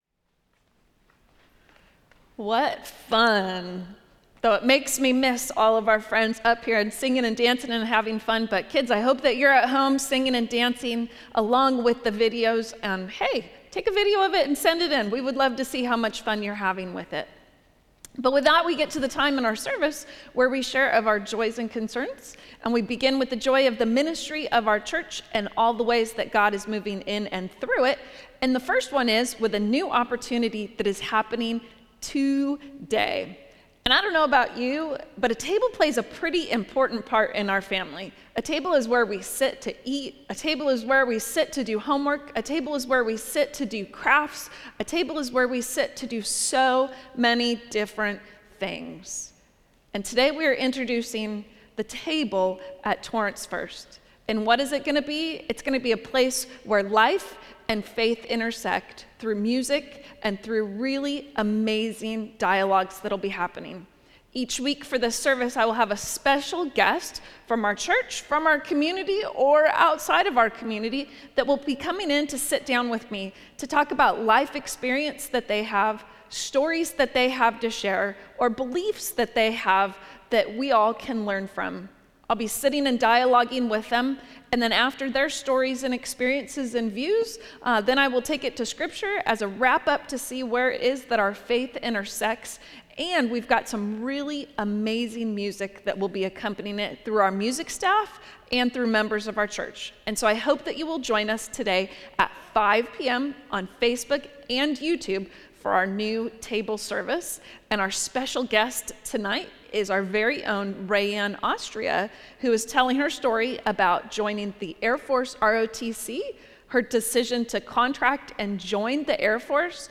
Joys and Concerns — Pastoral Prayer